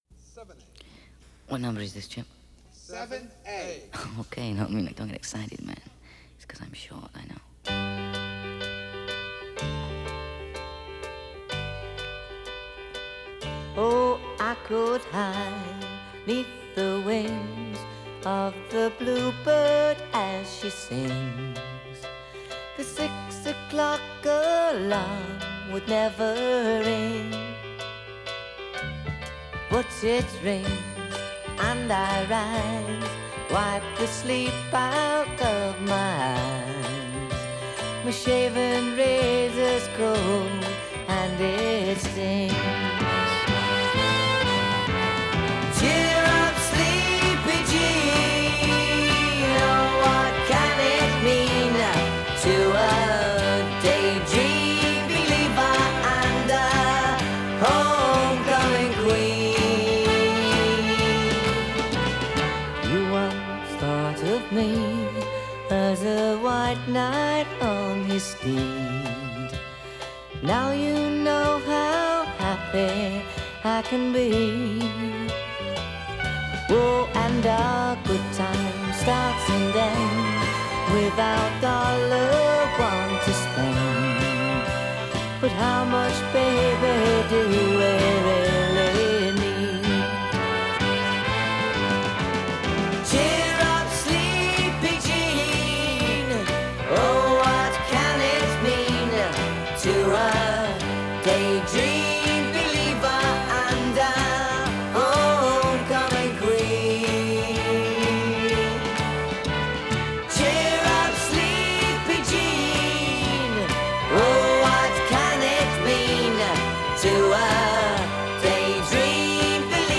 Transferred from high-res 24/192.